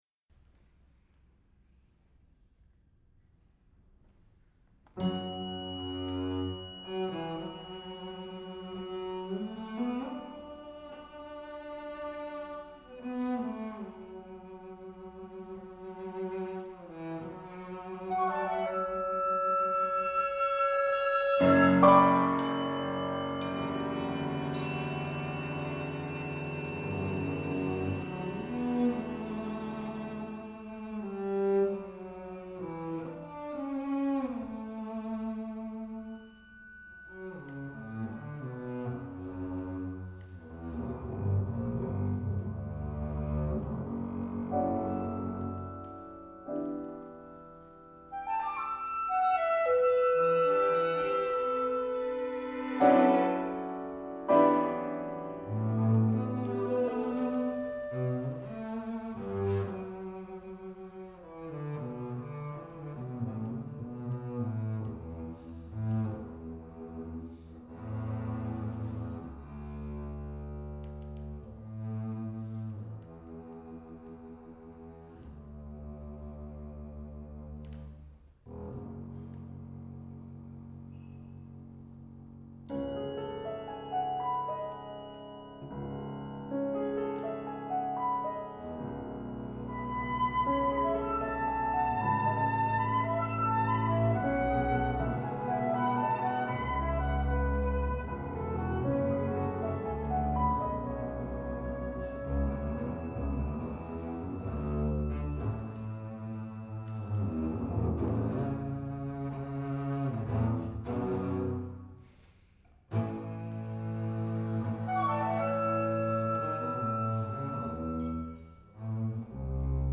contrabass